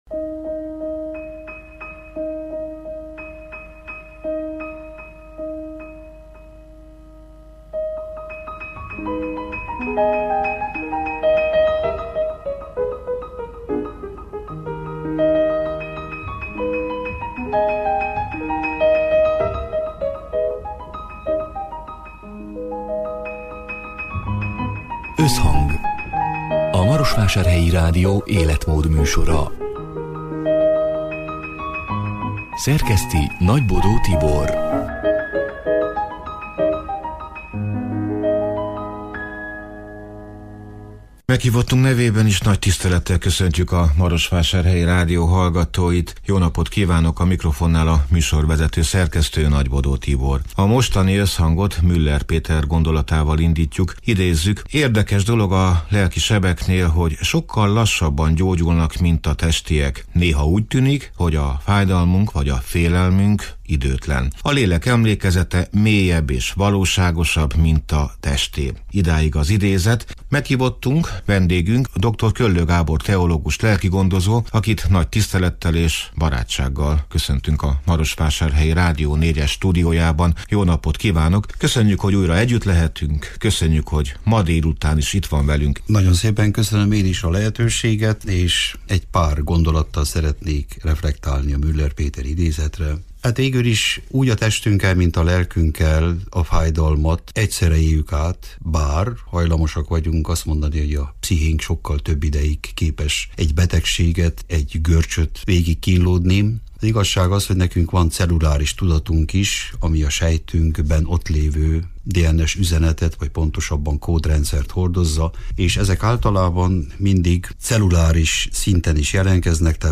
Az érzelmi intelligencia nem tananyag – mégis, a legfontosabb tudásunk lehet az élethez. A soron következő Összhangokban olyan beszélgetések várnak, amelyek megérintenek. Szó lesz arról, hogyan halljuk meg a másik embert – és önmagunkat.